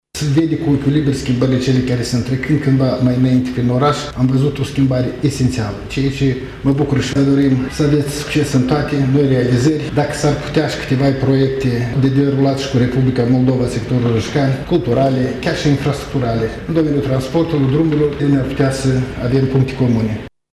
Întâlnirea a avut loc în sala de protocol a Primăriei.